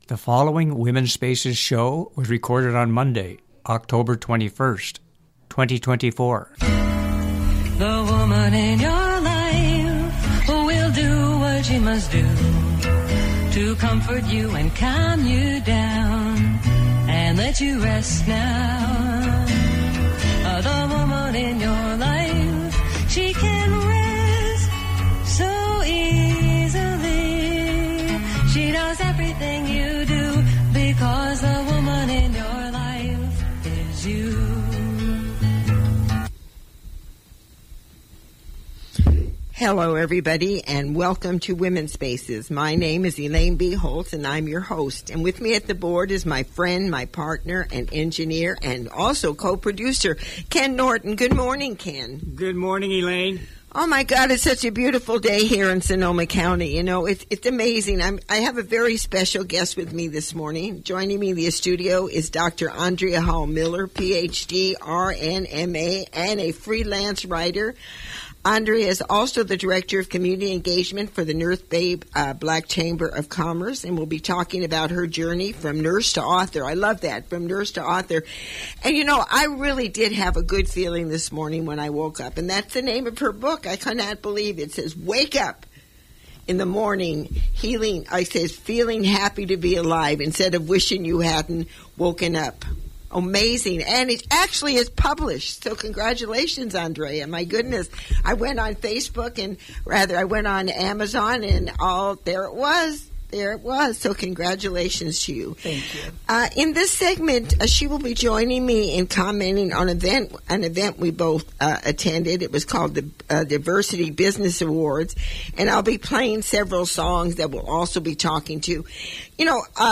October 21, 2024 Original Radio Show ID: WSA241021 Listen to the Show on the Mp3 Player below
I have a special guest this morning.